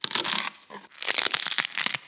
Cortar una acelga